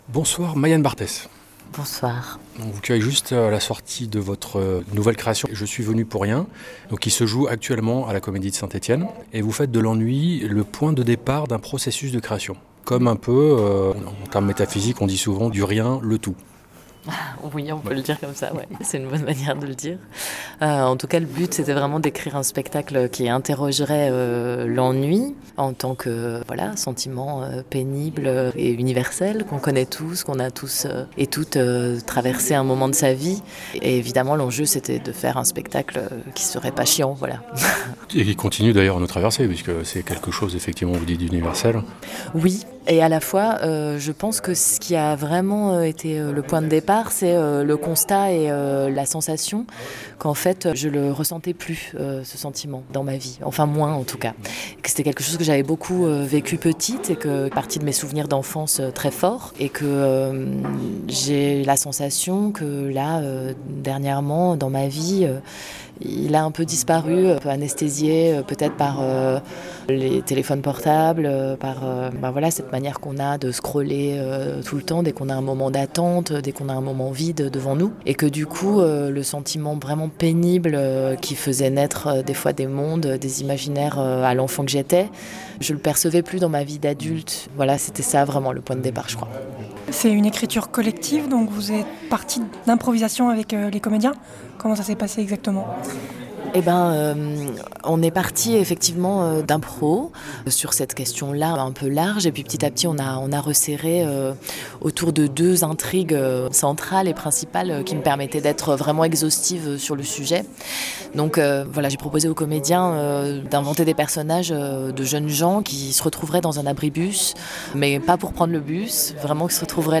Entretien
suite à la dernière représentation à Saint Étienne